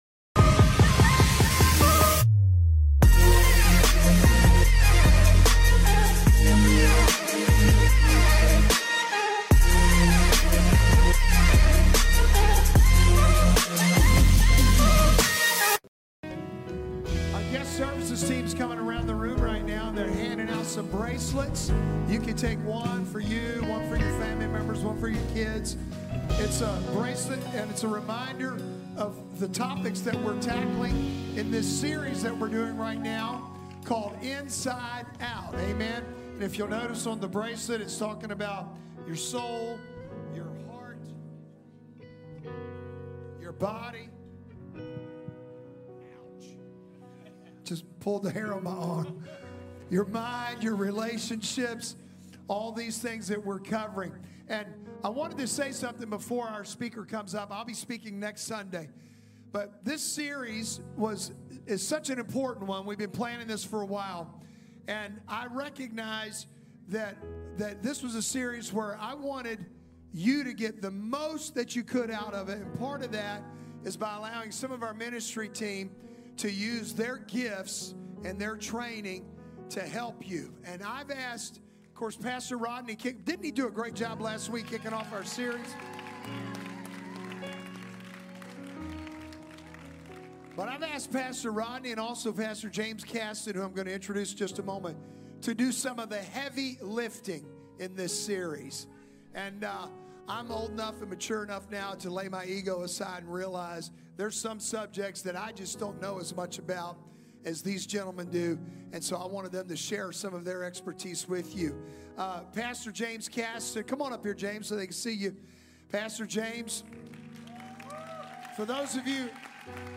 The sermon challenges us to reconsider our approach to mental health, emphasizing that we can't simply 'pray away' depression.